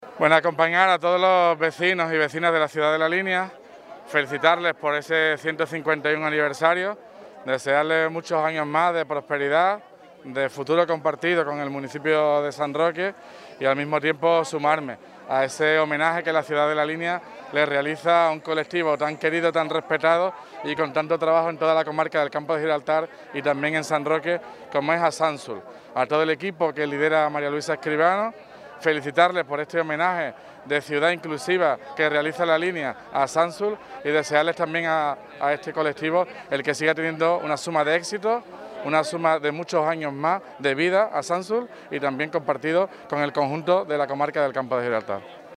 El alcalde, en la conmemoración del aniversario de La Línea de la Concepción
DIA_LA_LÍNEA_TOTAL_ALCALDE.mp3